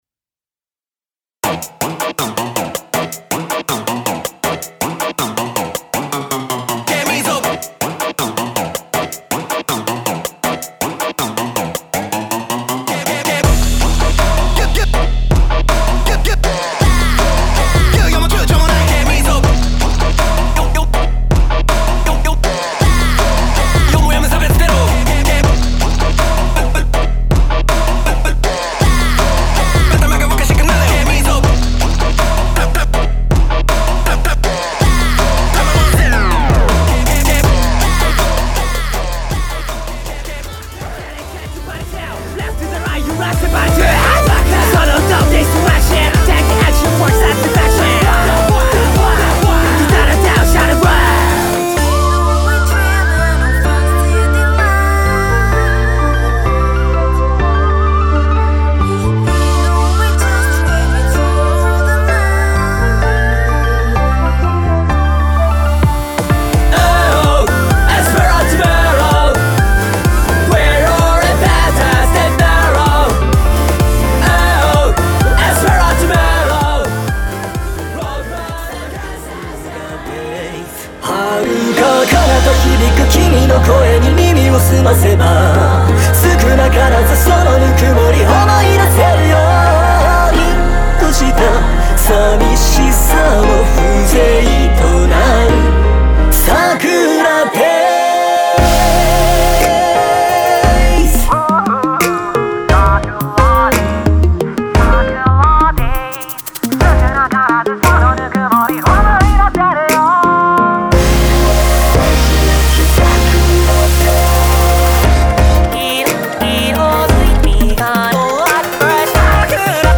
类型全长，混音集
风格类型嘻哈，其他电子
蜜瓜XFD